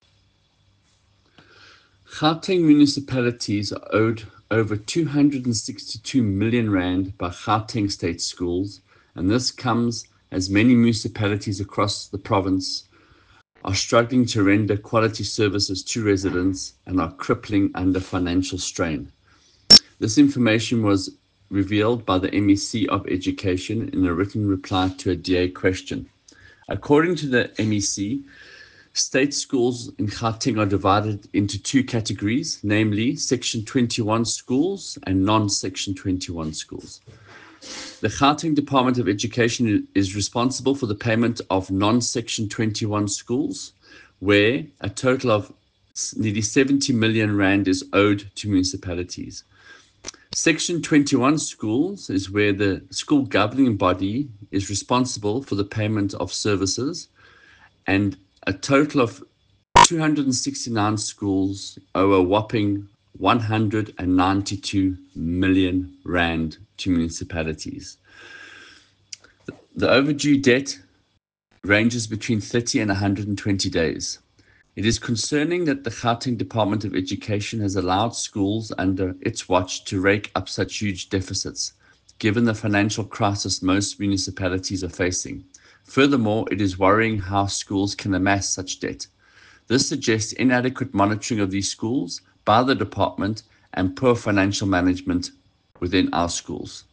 Issued by Michael Waters MPL – DA Gauteng Spokesperson for Education
soundbite by Michael Waters MPL.